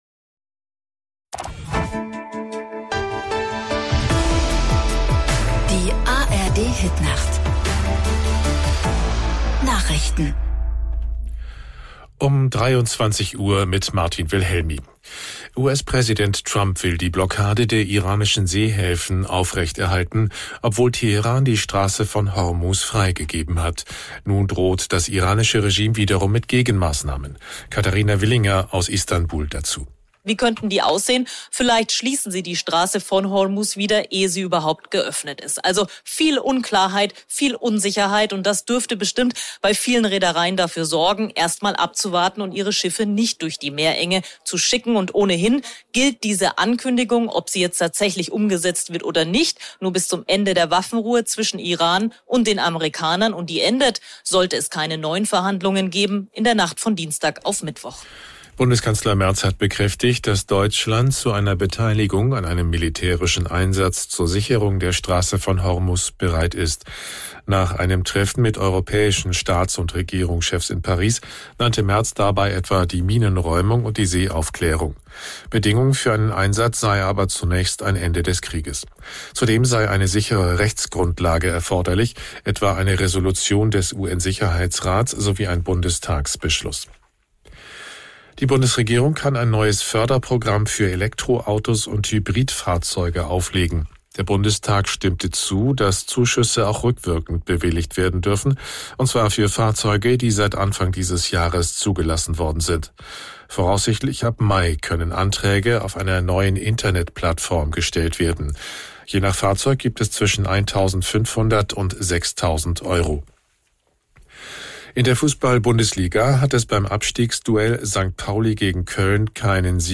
Nachrichten zum Nachhören
radionachrichten-bw.mp3